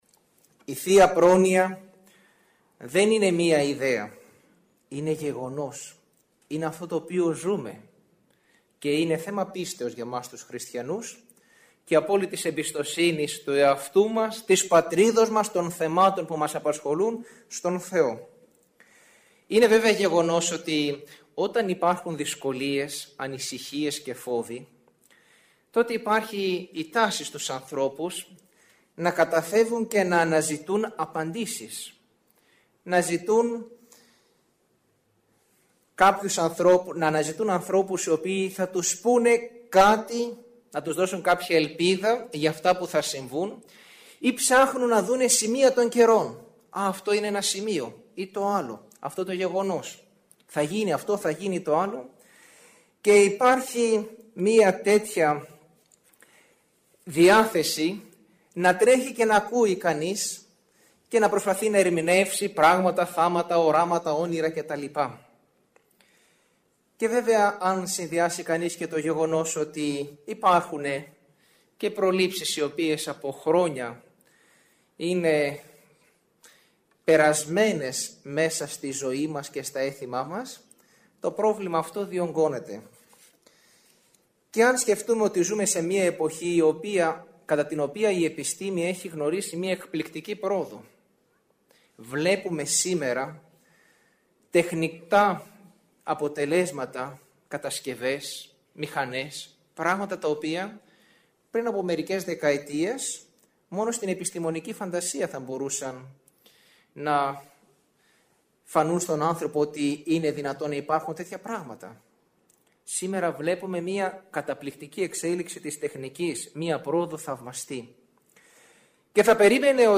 Προλήψεις, όνειρα και δεισιδαιμονίες – ηχογραφημένη ομιλία
Η ομιλία αυτή “πραγματοποιήθηκε” στην αίθουσα της Χριστιανικής Ενώσεως Αγρινίου τον Μάρτιο του 2011.